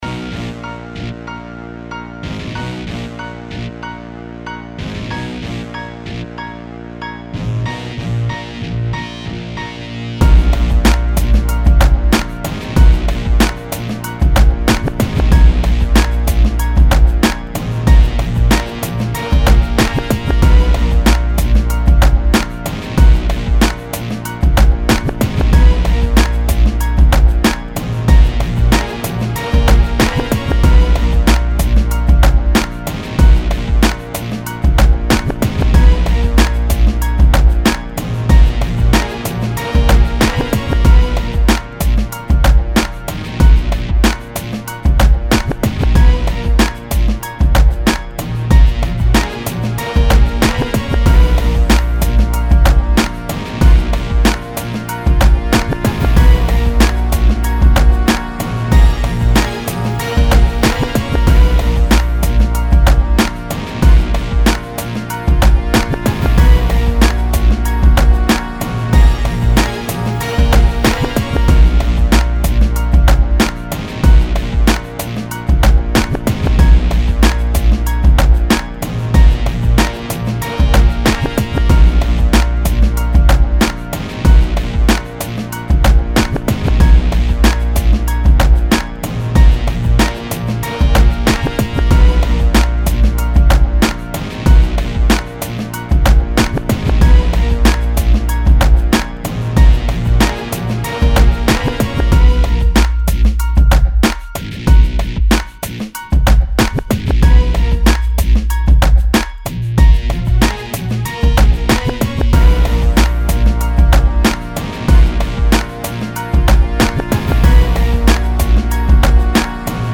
94 BPM. Anthem style with grimy brass and pianos.